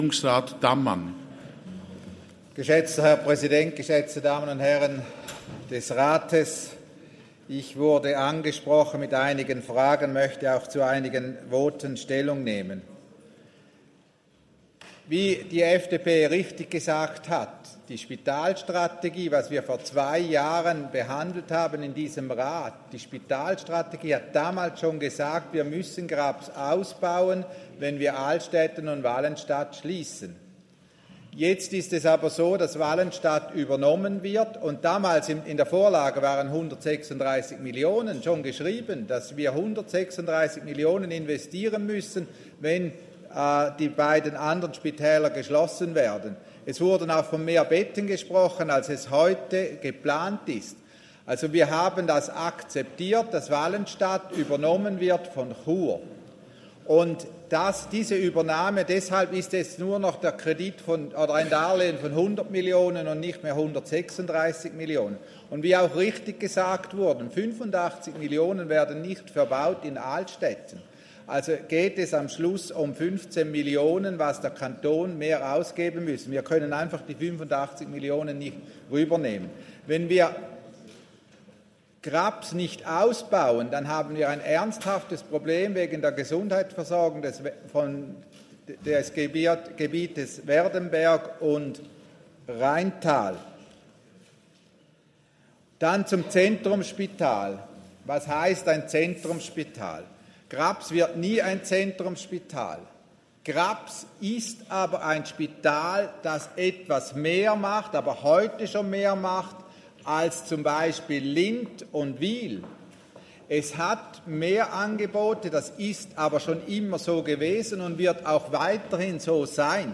30.11.2022Wortmeldung
Session des Kantonsrates vom 28. bis 30. November 2022